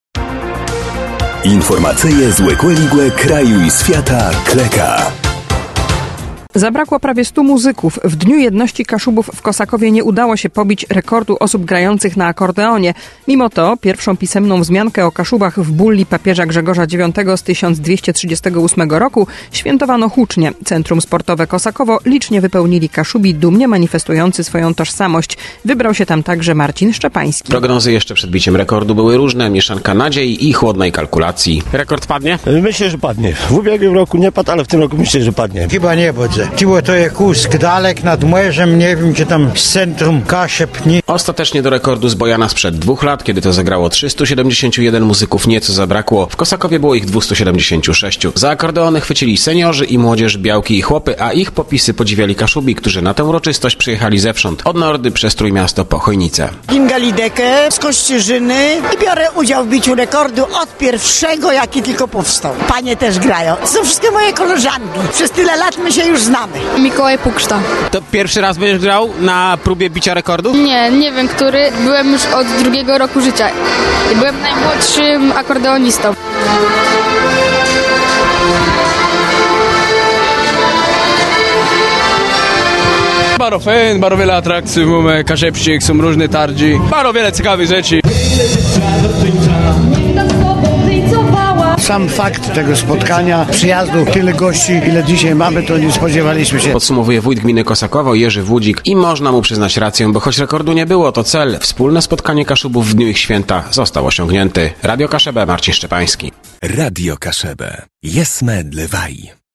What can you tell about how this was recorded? Zabrakło prawie stu muzyków – w Dniu Jedności Kaszubów w Kosakowie nie udało się pobić rekordu osób grających na akordeonie. 01_DJK-kosakowo-akordeony.mp3